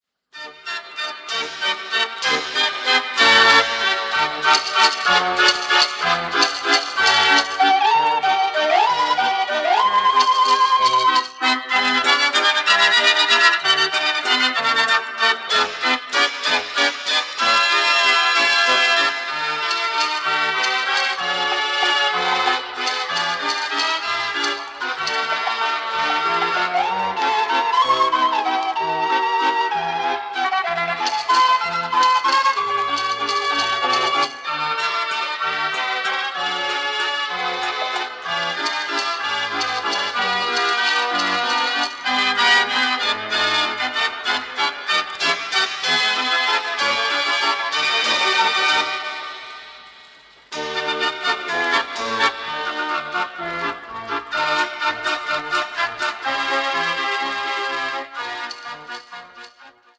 Deze zijde heeft een wat lichter en internationaal karakter.
Formaat 78 toerenplaat, 10 inch